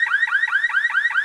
zp_caralarm.wav